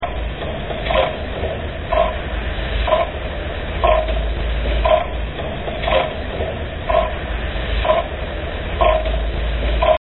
Заценить, как тикает механизм часов нашей башни в здоровом состоянии, можно